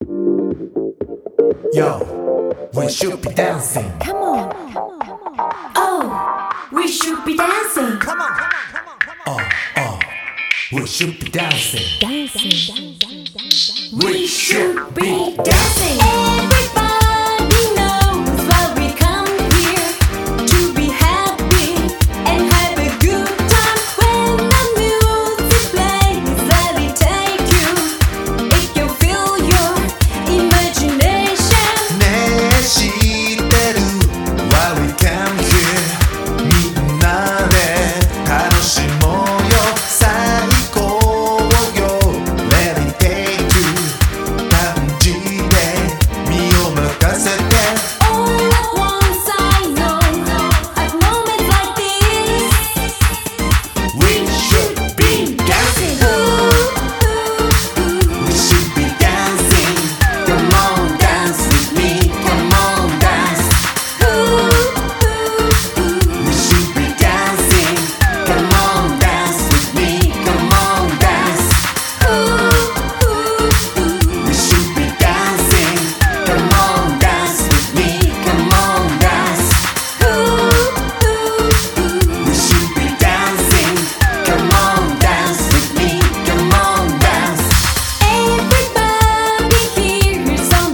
# POP# CITY POP / AOR# CLUB# 和モノ